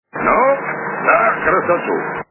» Звуки » Из фильмов и телепередач » Особенности национальной охоты - Ну, за красоту!
При прослушивании Особенности национальной охоты - Ну, за красоту! качество понижено и присутствуют гудки.